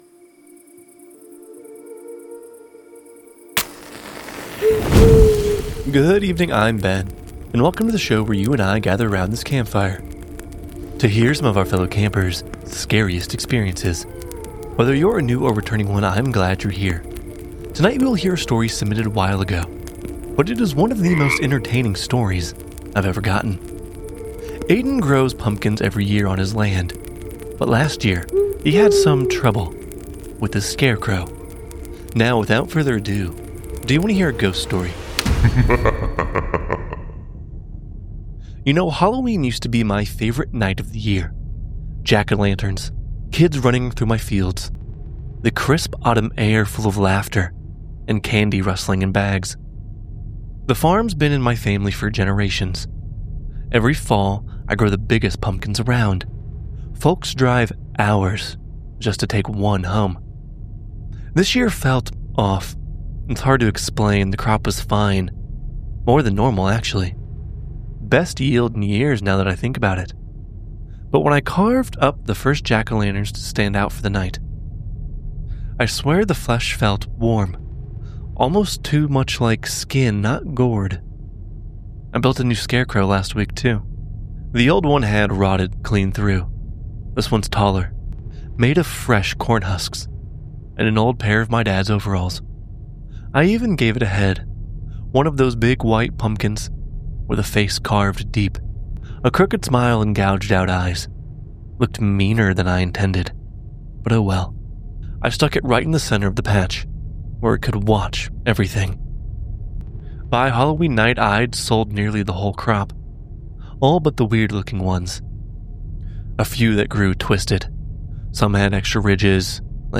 Narrated by:
Sound Design by: